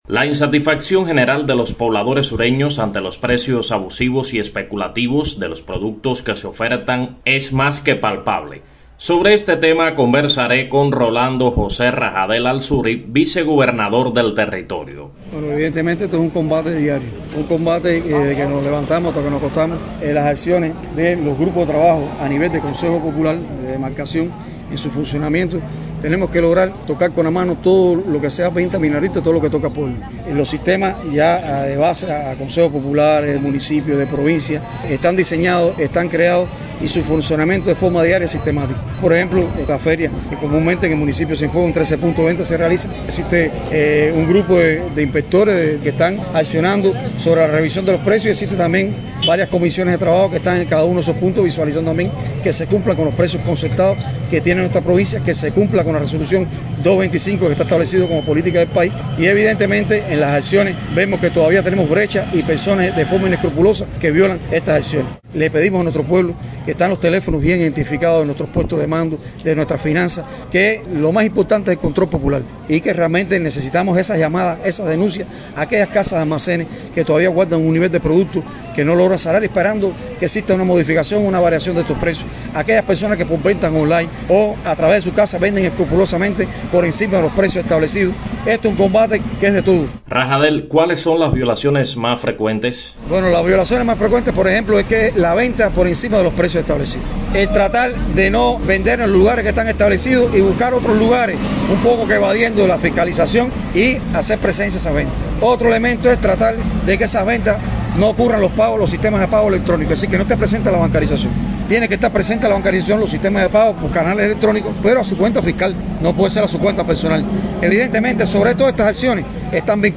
En la provincia de Cienfuegos mantienen un estricto control en el enfrentamiento a los precios minoristas abusivos y especulativos. A pesar de que no se perciben resultados halagüeños en todos los frentes, el trabajo continúa, así aseguró a la radio Rolando José Rajadel Alzuri, vicegobernador del territorio.